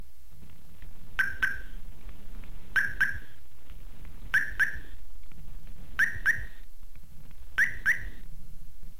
購入したキットは スーパーサウンド+ハザードアンサーバックキット（2段階音量設定） というもの。このキットは、ハザードランプの点滅と音で施錠・解錠の完了を知らせてくれます。
• サイレン
どのような音がするかというと